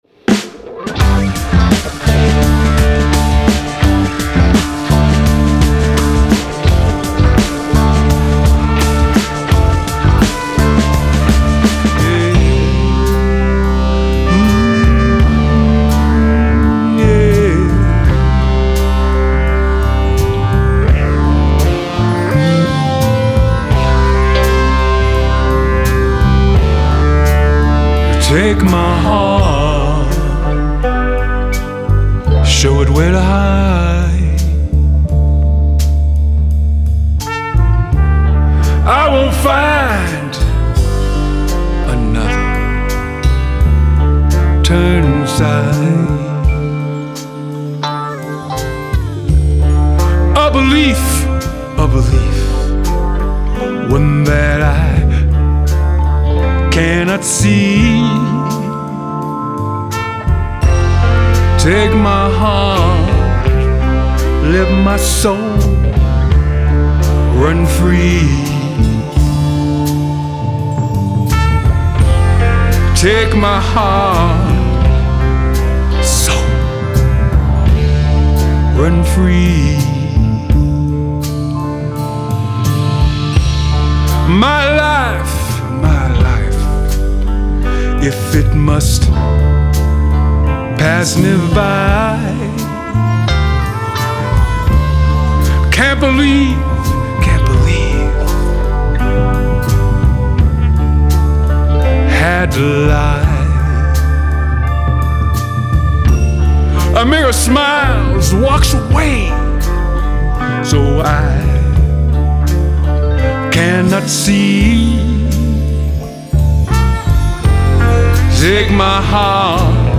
Genre : Rock, Blues